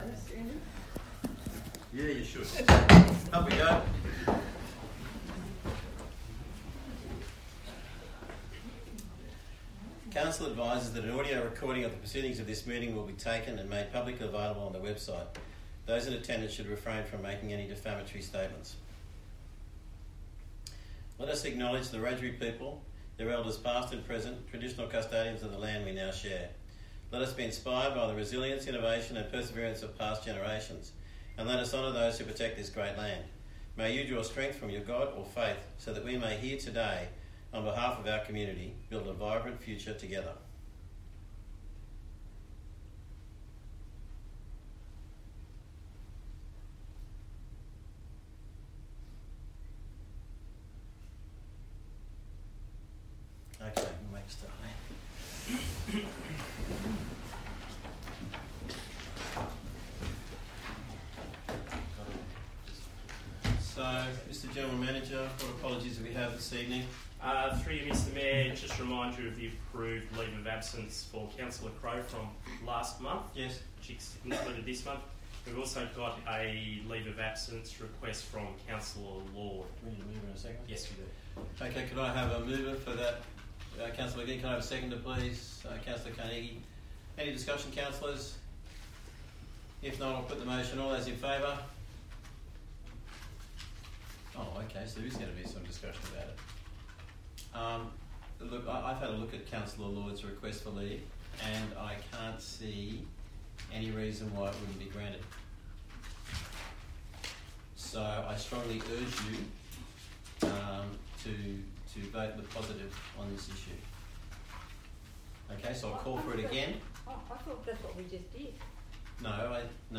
15 April 2025 Ordinary Council Meeting
Bland Shire Council Chambers, 6 Shire Street, West Wyalong, 2671 View Map